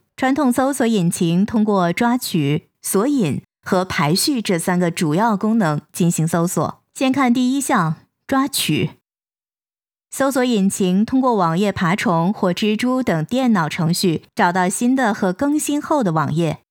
Chinese_Female_045VoiceArtist_2Hours_High_Quality_Voice_Dataset